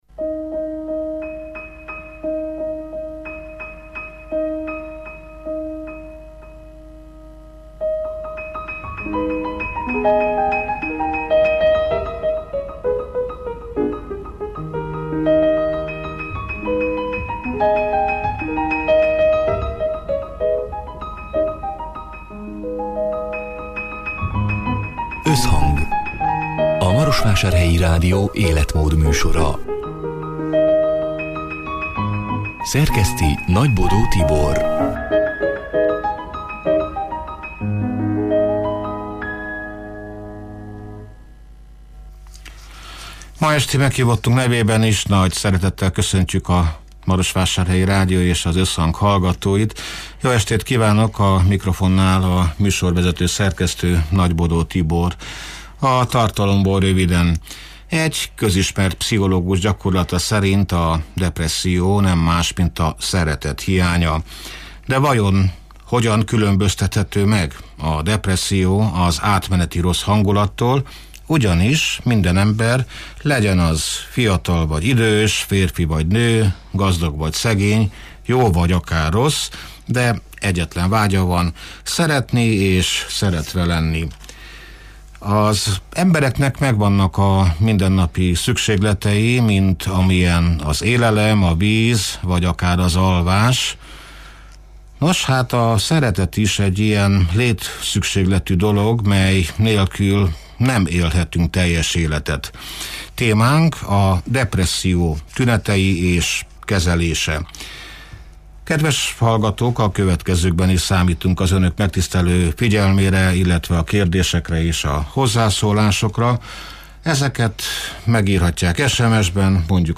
(elhangzott: 2025. február 12-én, szerdán délután hat órától élőben)